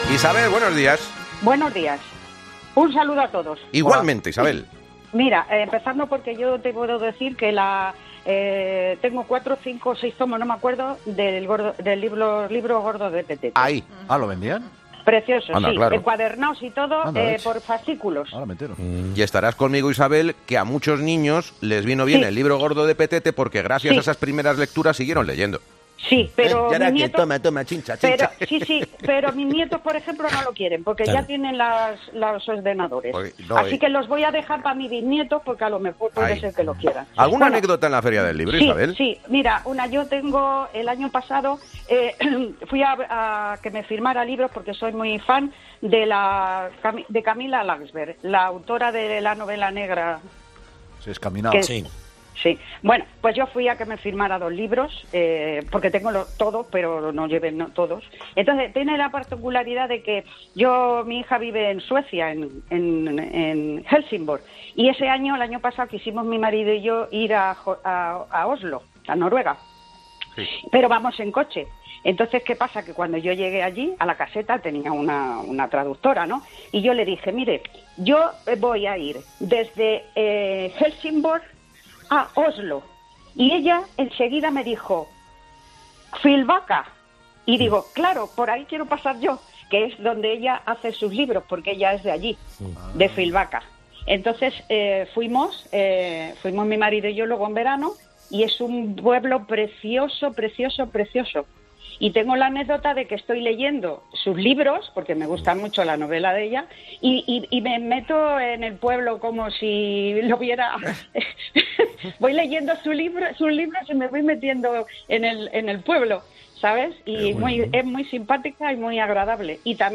Coincidiendo con la Feria del Libro, este lunes los 'fósforos' de 'Herrera en Cope' se han pronunciado sobre las obras que más les han marcado, aunque también han llamado escritores noveles y otros con más recorrido para dar a conocer sus creaciones literarias.